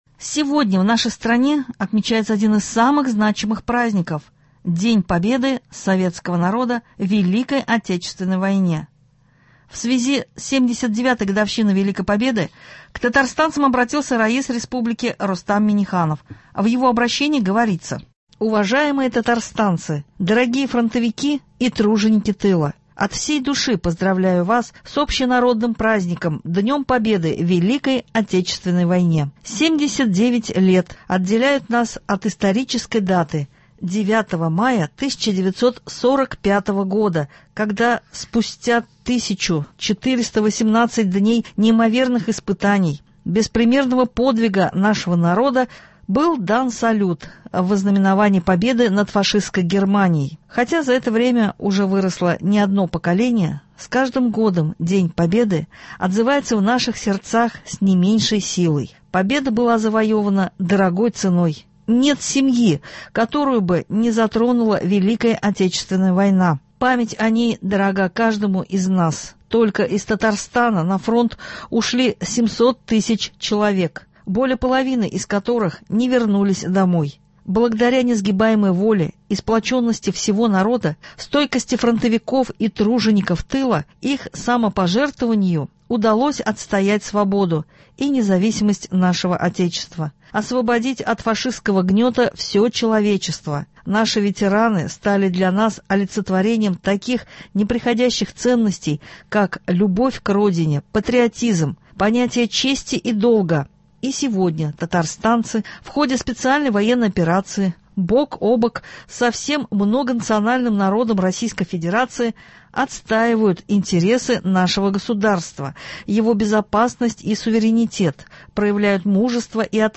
Новости (09.05.24)